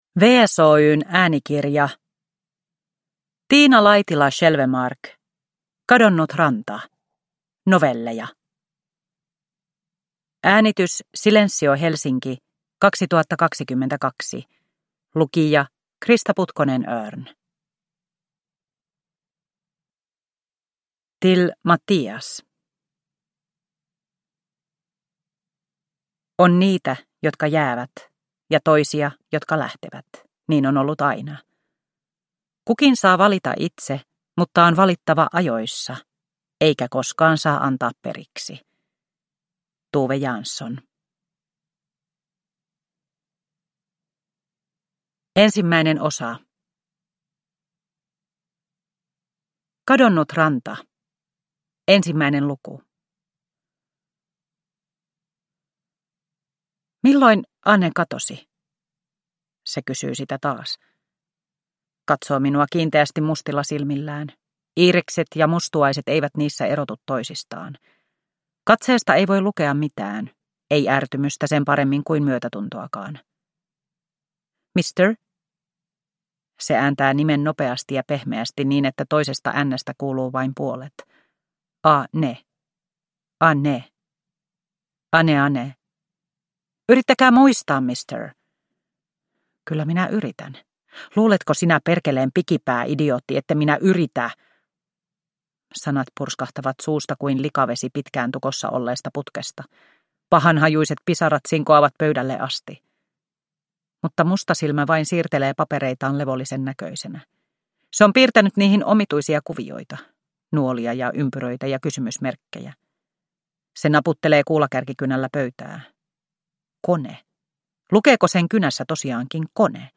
Kadonnut ranta – Ljudbok – Laddas ner